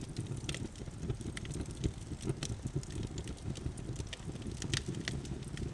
Synthesized examples twice the duration of the originals.
Fire
Fire.wav